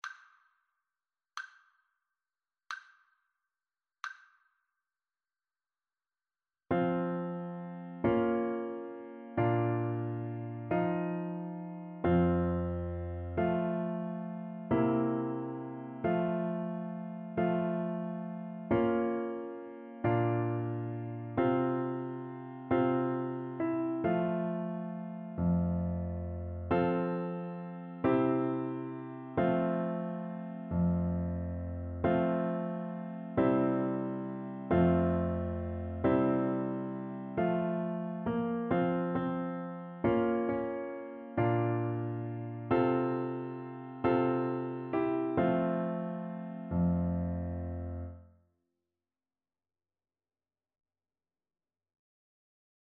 Play (or use space bar on your keyboard) Pause Music Playalong - Piano Accompaniment Playalong Band Accompaniment not yet available reset tempo print settings full screen
Traditional Music of unknown author.
F major (Sounding Pitch) (View more F major Music for Flute )
One in a bar .=45